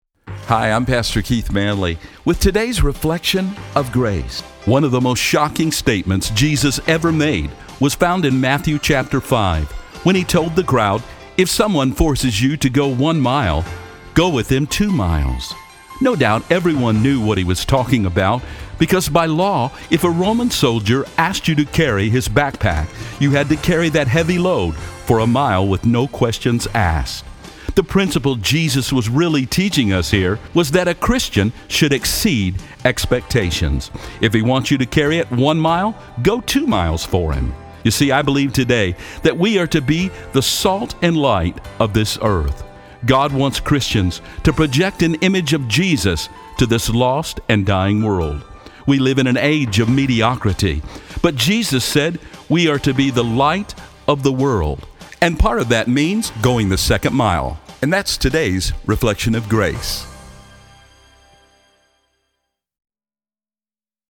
These spots air locally on 93.3 FM and on the Wilkins Radio Network heard in 27 Radio Stations around the country.